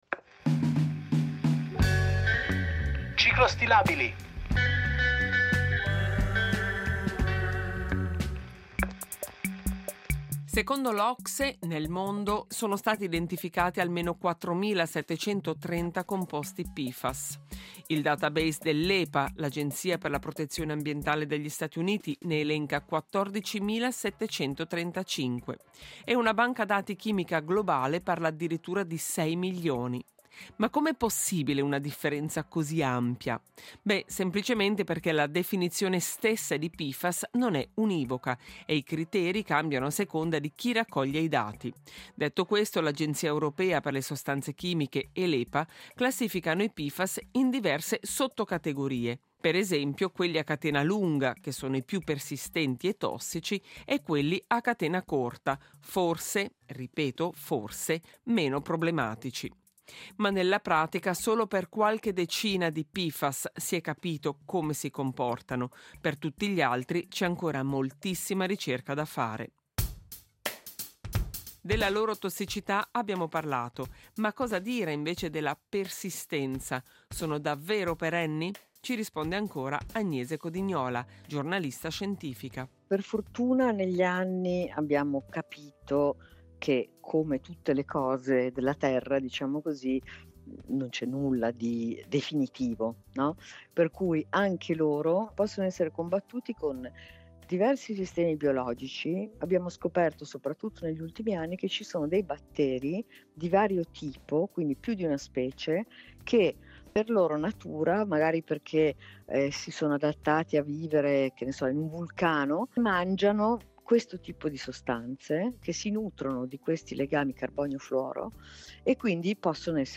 ha intervistato la giornalista scientifica